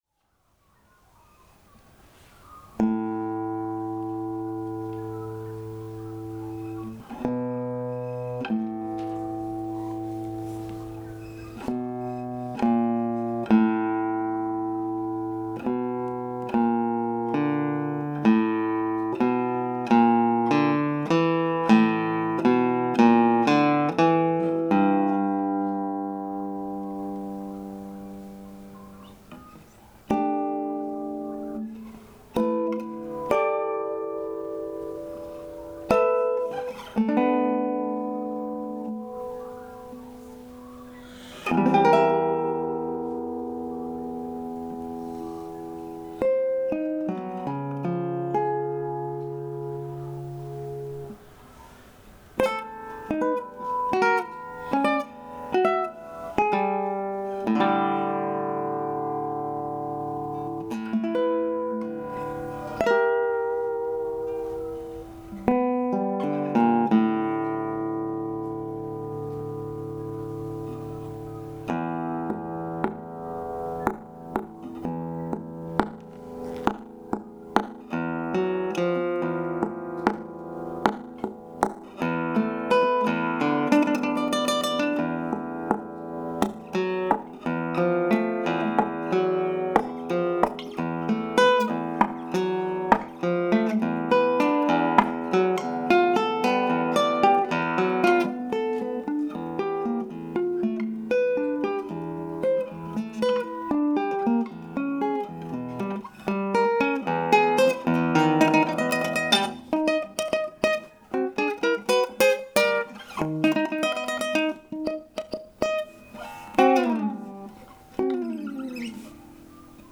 Solo
Insect Reflection, for guitar (2014). 2nd Place in the Matt Withers Young Australian Music Composition Competition, 2014